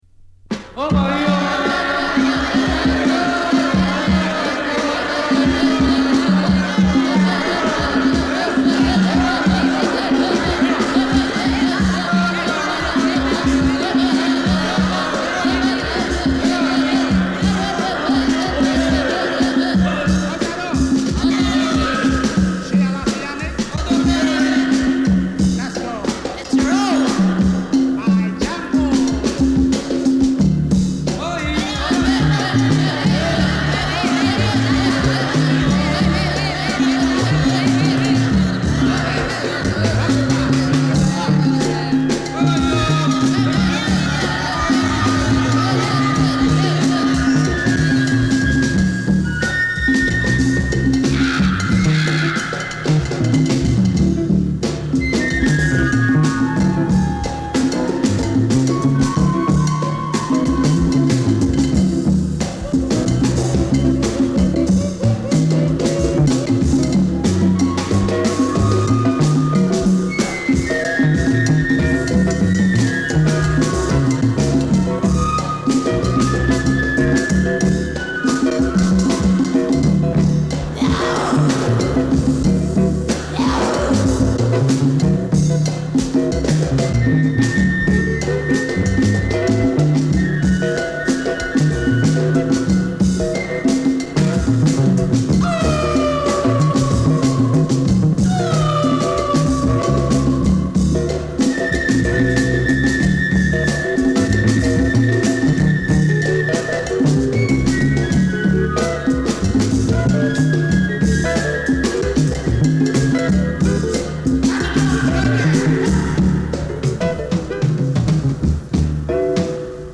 アルバム通してリバーブがかったヘンテコ度高めの1枚。